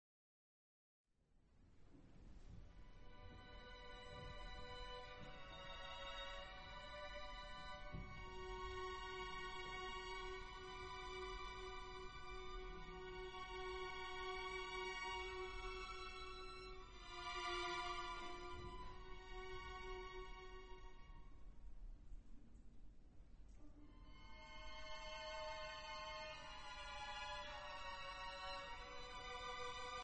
• 782.1 FORME VOCALI DRAMMATICHE OPERE
• registrazione sonora di musica